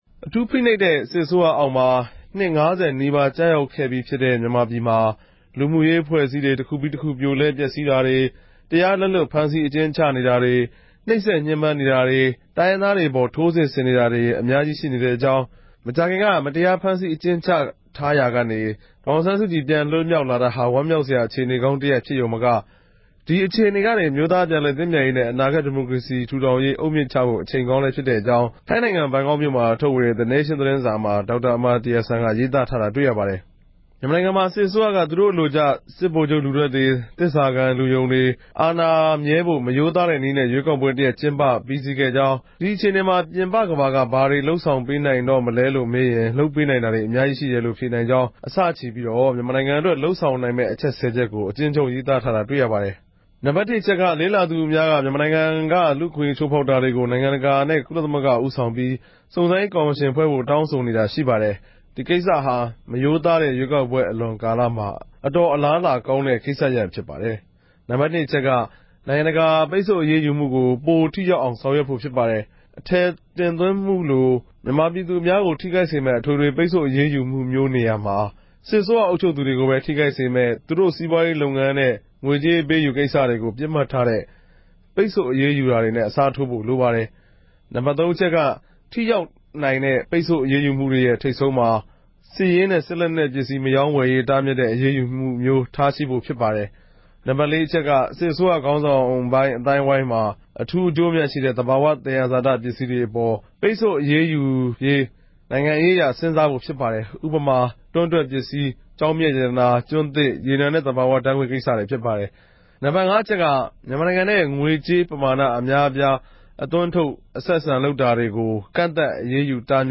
စုစည်းတင်ပြချက်။